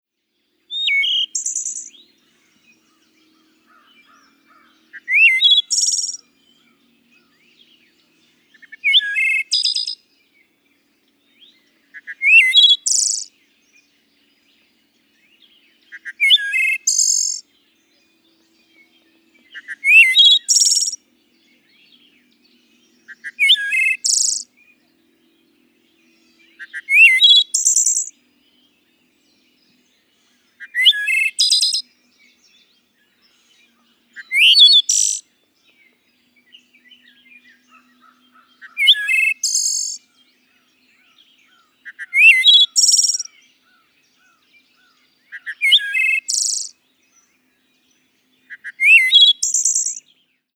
Wood Thrush
BIRD CALL: WOOD FLUTE-LIKE, MELODIC SONG WITH VARIABLE PATTERNS AND “CAW CAW CAW” IN THE BACKGROUND.
Wood-thrush-call.mp3